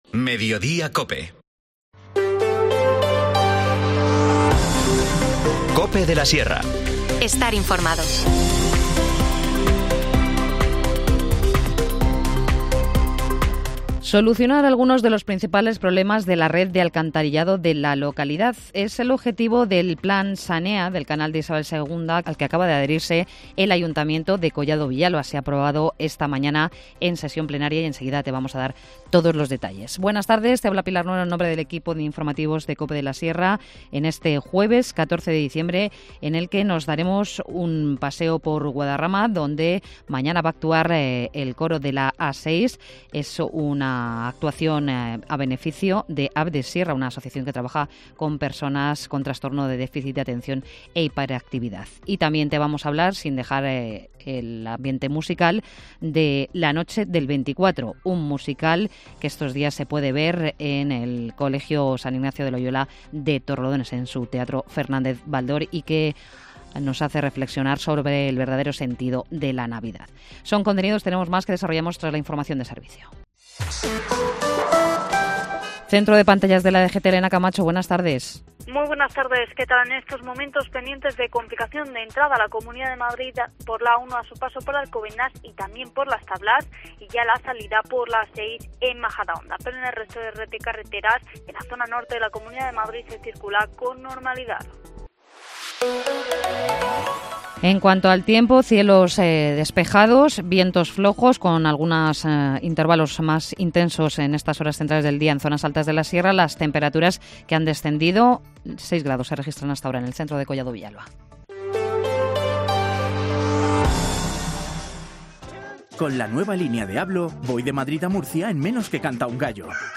Informativo | Mediodía en Cope de la Sierra, 14 de diciembre de 2023